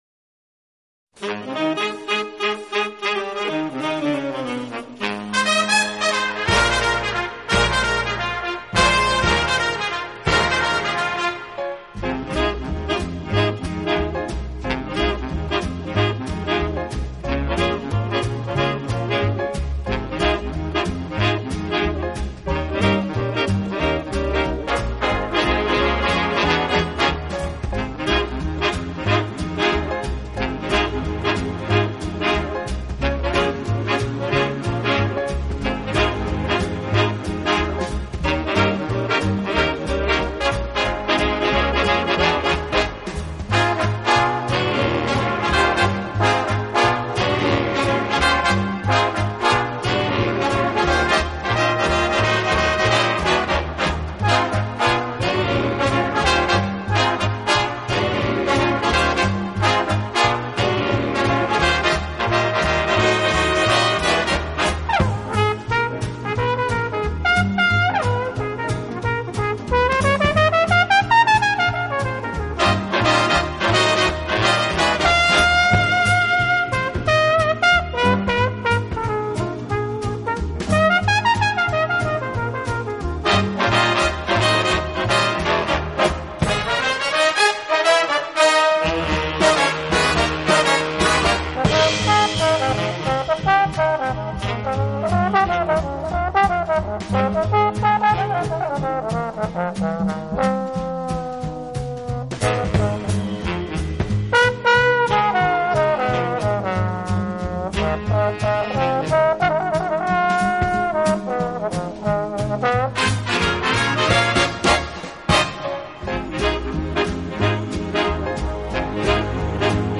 轻音乐合辑